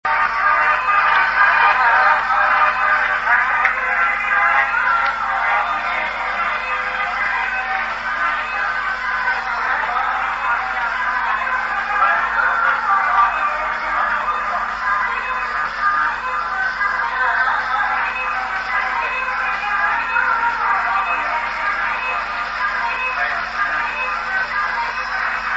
Trance track 'my life'
(sry for bad quality )